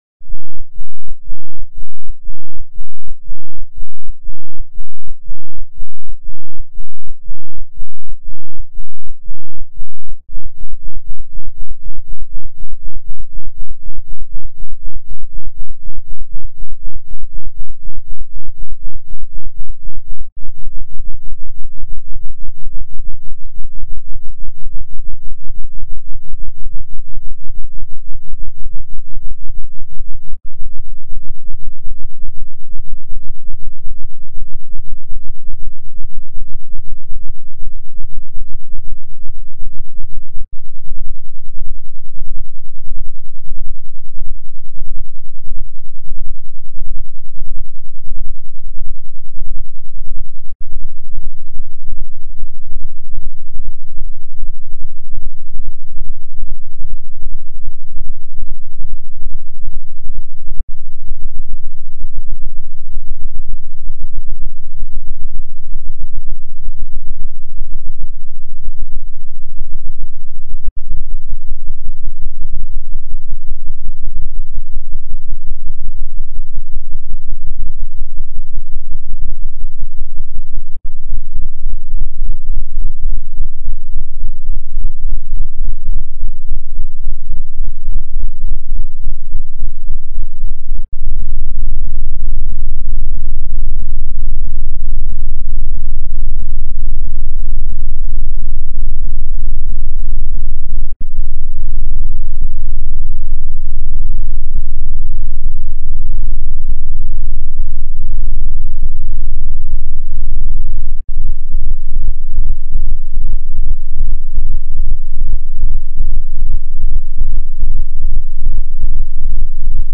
Bass Test Frequency Sweep 1 Hz To 35 Hz.mp3